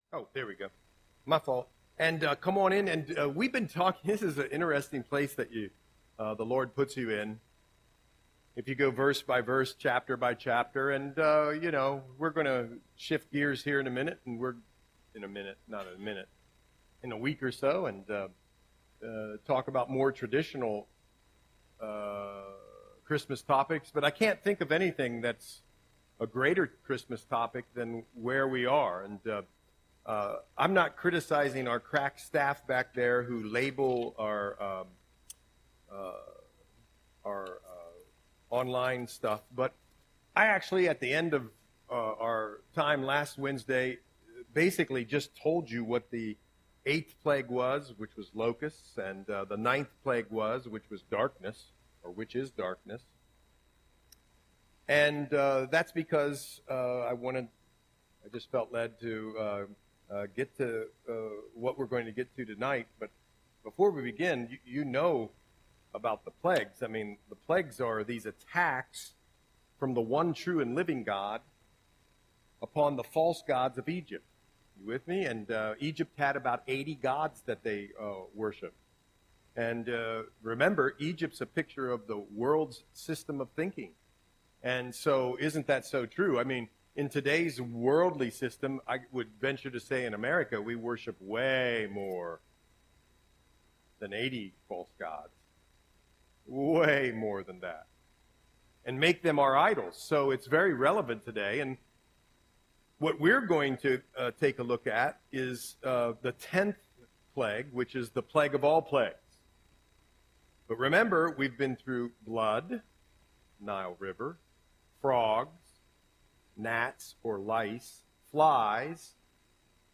Audio Sermon - December 18, 2024